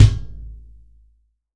TUBEKICKO1-S.WAV